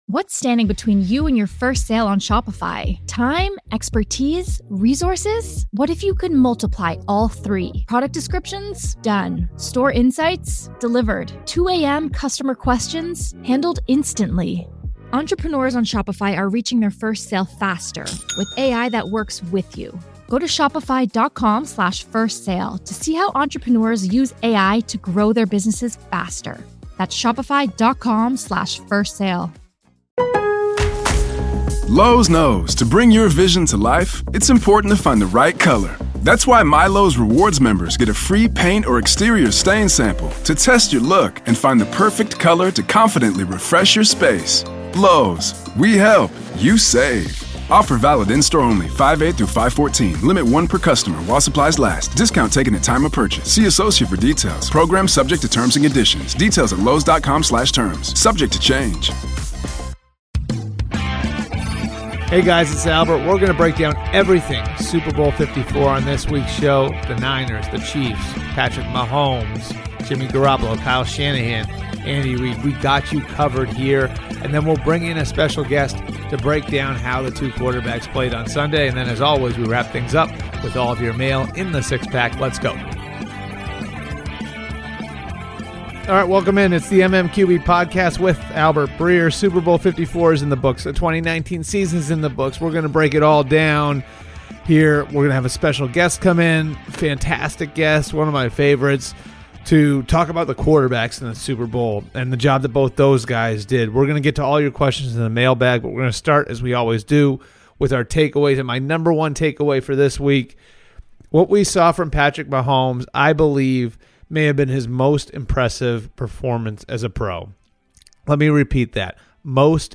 Albert Breer breaks down the Chiefs comeback win in Super Bowl LIV before talking to former NFL quarterback Dan Orlovsky on the performances by Patrick Mahomes and Jimmy Garoppolo, and of course, the mailbag